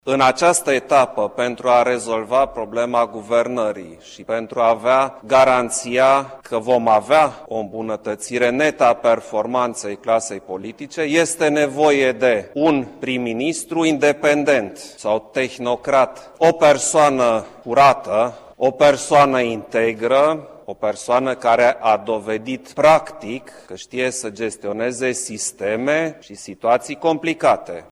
Preşedintele Klaus Iohannis a precizat că a făcut această nominalizare pentru că România are nevoie acum de un prim-ministru independent şi integru şi cu experienţă managerială.